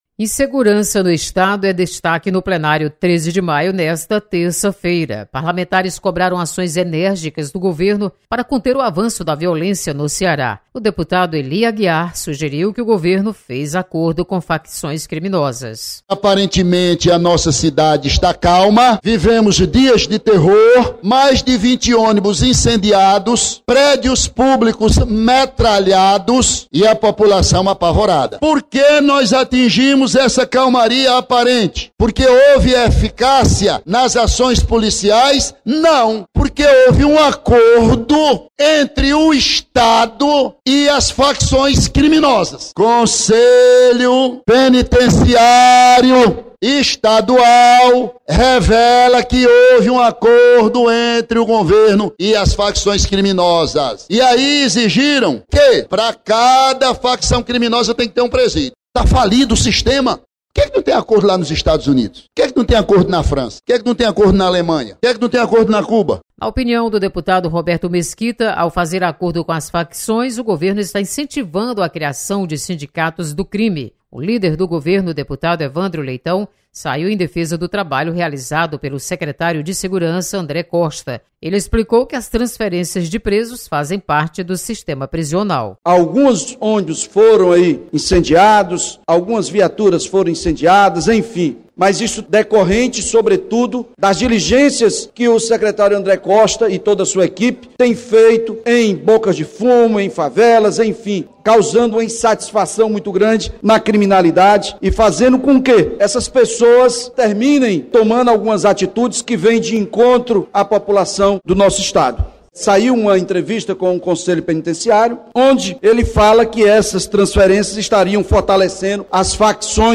Deputados comentam sobre casos de violência no estado.